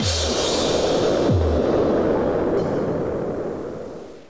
splash.wav